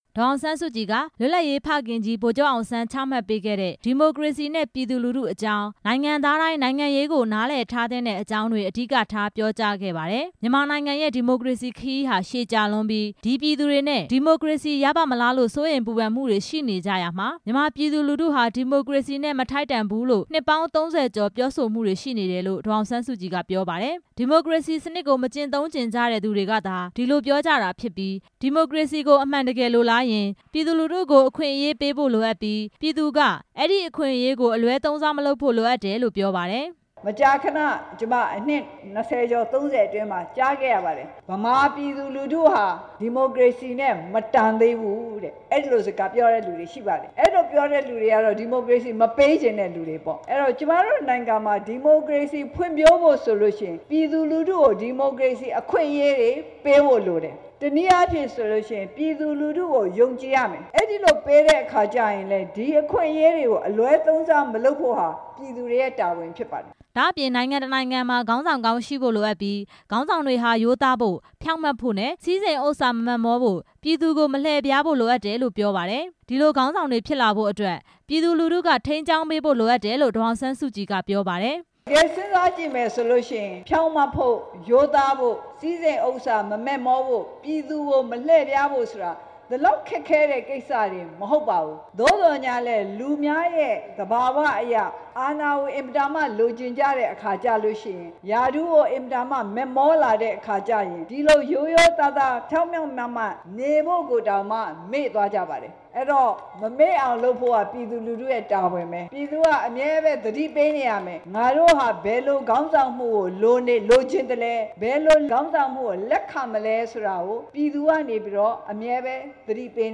ပဲခူးတိုင်းဒေသကြီး ရွှေမောဓောစေတီအနောက်ဘက်မုဒ်ကကွင်းမှာ ဗိုလ်ချုပ်နှစ်တစ်ရာပြည့်အကြို လူထုဟောပြောပွဲကို ဒီနေ့ ကျင်းပခဲ့ရာ အမျိုးသားဒီမိုကရေစီအဖွဲ့ချုပ် ဥက္ကဌ ဒေါ်အောင်ဆန်းစုကြည် တက်ရောက်မိန့်ခွန်းပြောကြားခဲ့ပါတယ်။